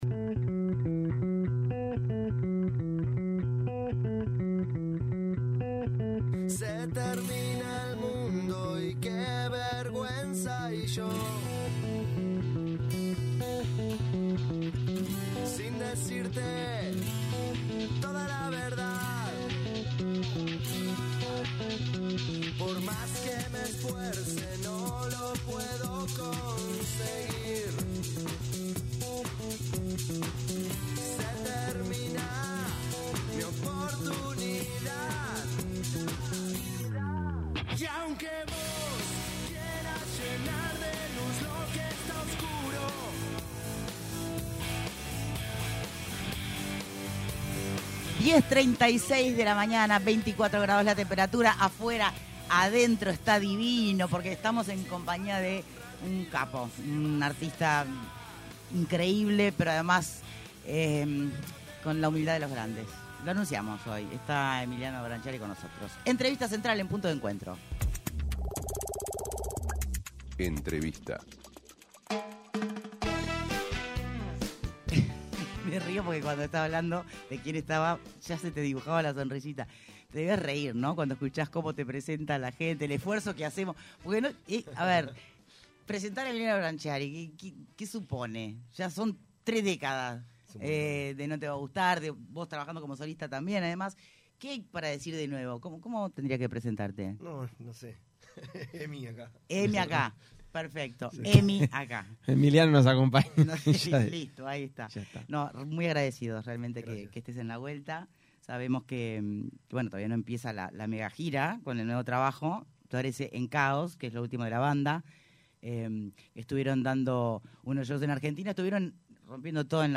ENTREVISTA: EMILIANO BRANCCIARI
En entrevista con Punto de Encuentro, el compositor, guitarrista y cantante de No Te Va Gustar, Emiliano Brancciari se refirió a cómo es escribir canciones y resignificar algunas que realizó siendo adolescente o muy joven.